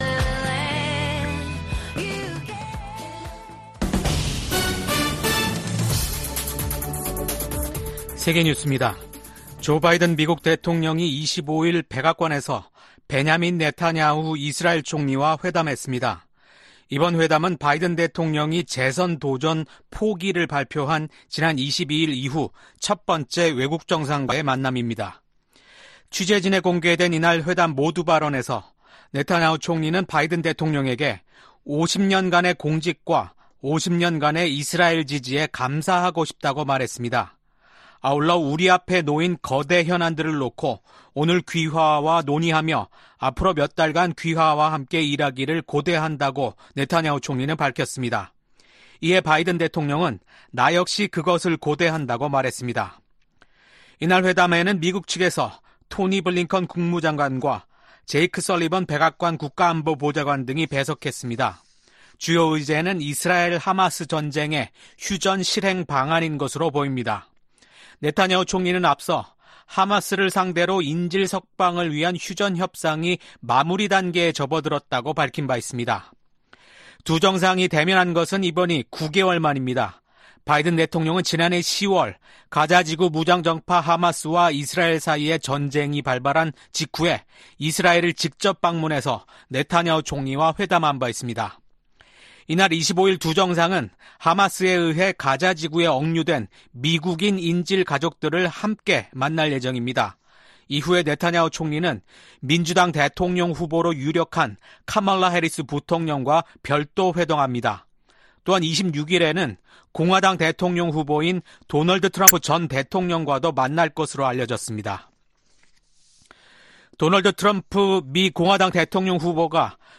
VOA 한국어 아침 뉴스 프로그램 '워싱턴 뉴스 광장' 2024년 7월 26일 방송입니다. 민주당 대선 후보직에서 사퇴한 조 바이든 미국 대통령이 대국민 연설을 통해 민주주의의 수호화 통합을 강조했습니다. 미국 정부가 북한의 미사일 관련 기술 개발을 지원한 중국 기업과 중국인에 신규 제재를 부과했습니다. 북한과 러시아의 관계가 급속도로 가까워지는 가운데 한국과 중국은 외교차관 전략대화를, 북한은 벨라루스와 외교장관 회담을 가졌습니다.